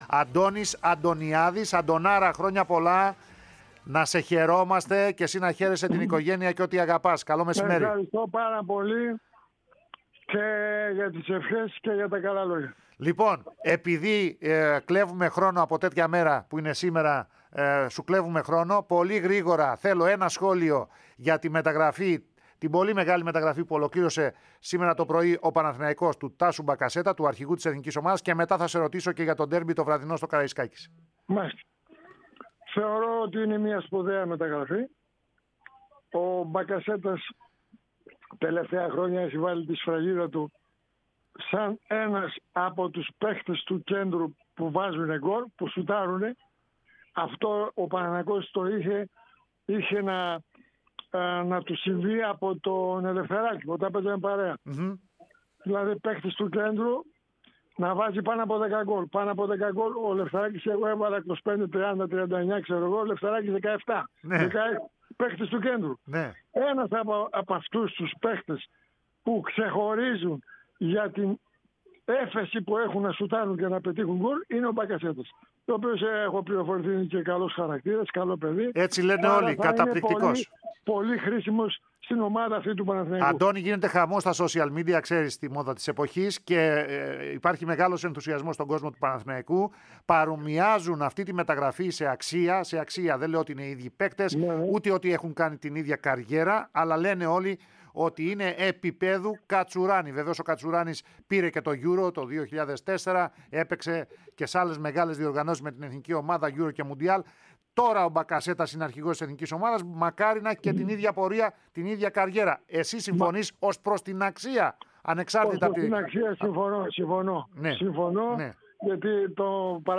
Ακούστε αναλυτικά όσα είπε ο Αντώνης Αντωνιάδης στην ΕΡΑ ΣΠΟΡ: